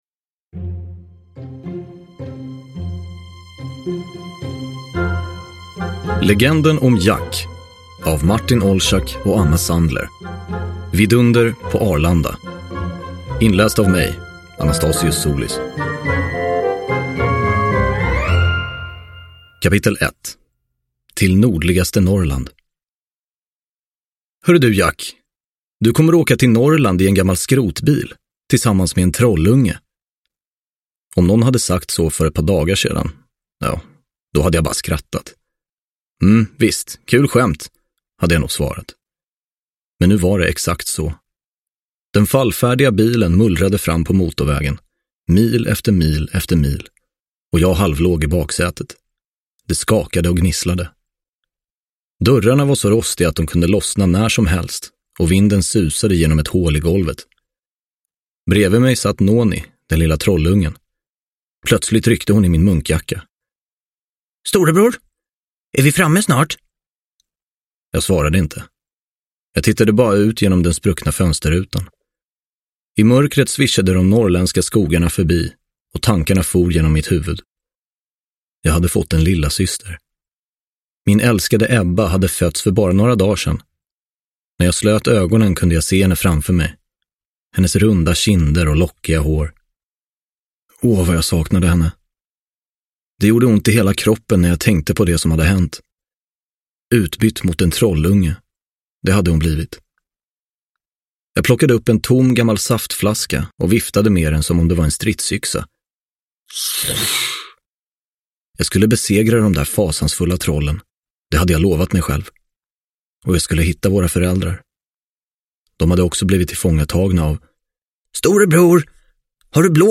Vidunder på Arlanda – Ljudbok – Laddas ner